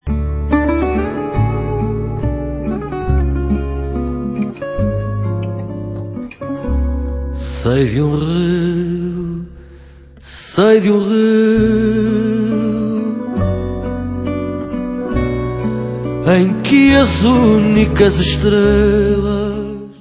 sledovat novinky v oddělení World/Fado